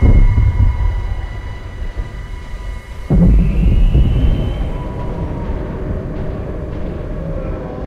Alarm2_6.ogg